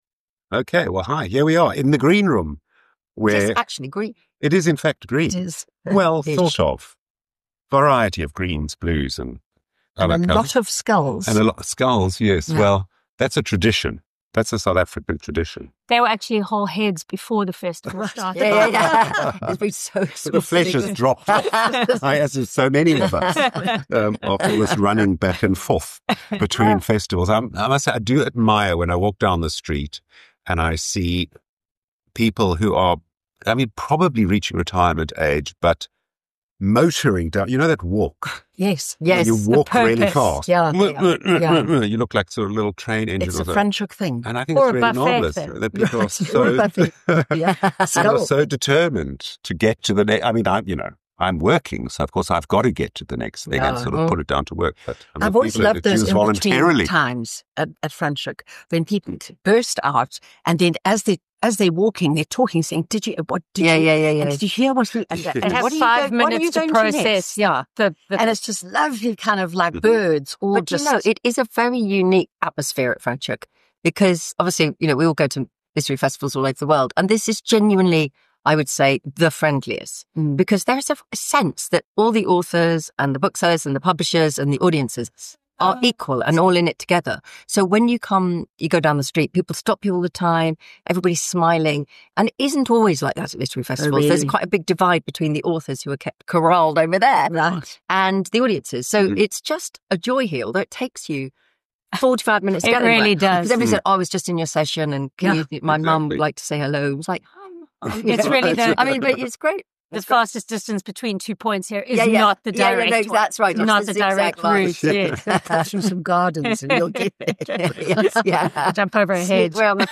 Pagecast along with a host of book people take a seat in the vibrant courtyard of Smitten Cafe surrounded by a soirée of literary buzz. Reflecting on the festival buzz in the air, standout moments, and the nostalgic love affair with Johannesburg, all against the breathtaking beauty of Franschhoek.